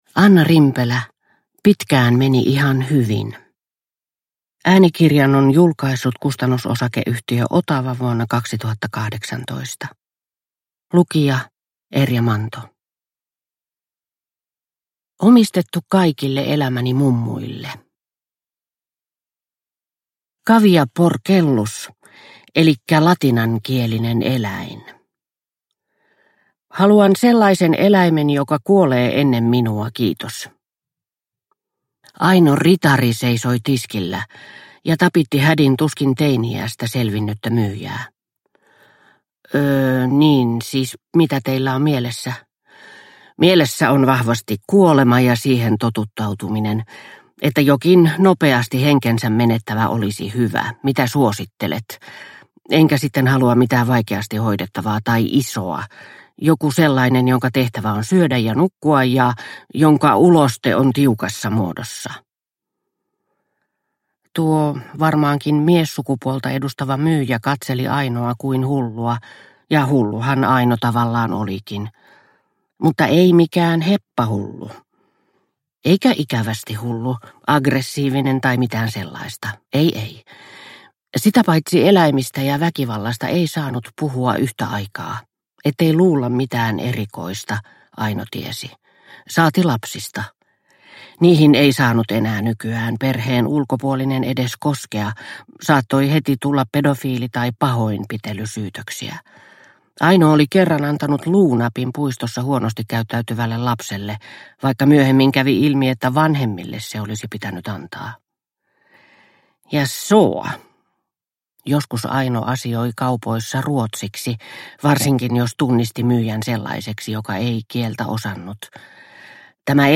Pitkään meni ihan hyvin – Ljudbok